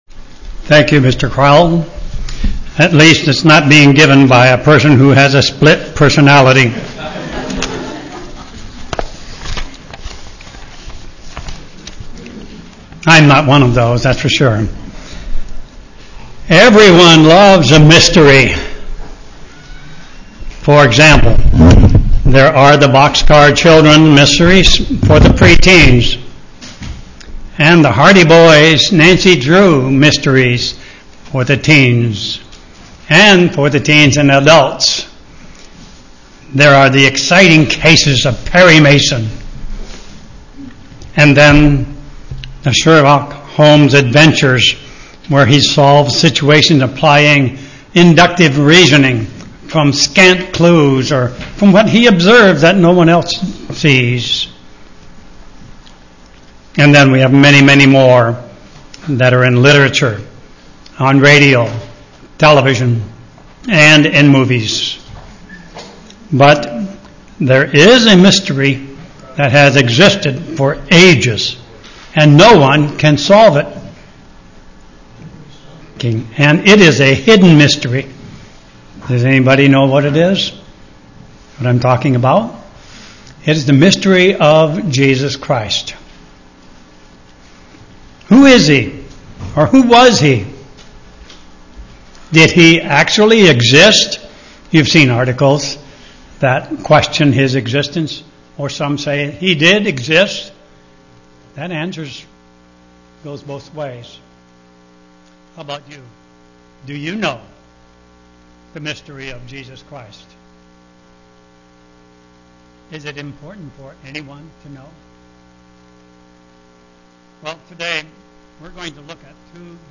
Sermons
Given in Olympia, WA